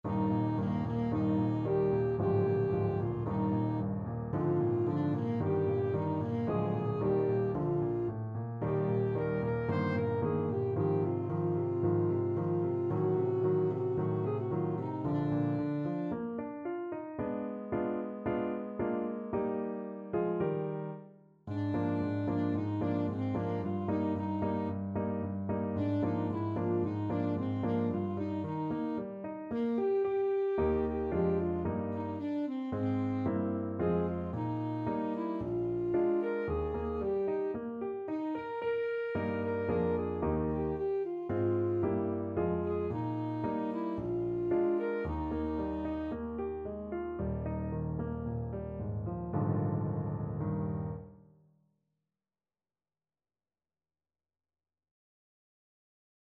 Alto Saxophone
2/4 (View more 2/4 Music)
Eb major (Sounding Pitch) C major (Alto Saxophone in Eb) (View more Eb major Music for Saxophone )
~ = 56 Affettuoso
Classical (View more Classical Saxophone Music)